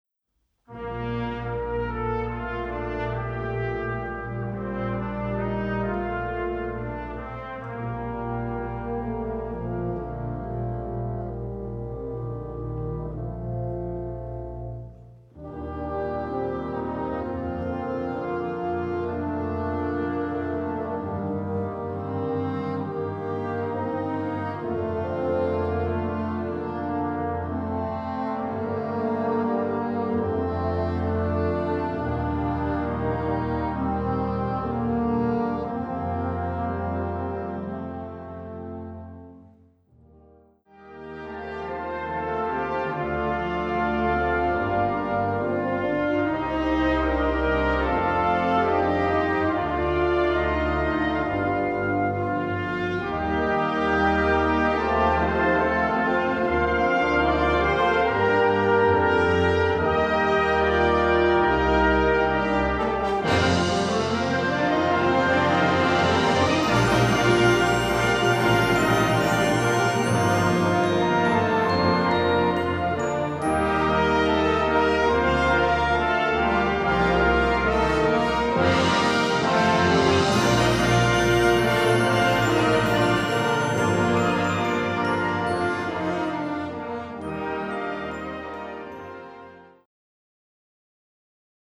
Gattung: Kirchenmusik
Besetzung: Blasorchester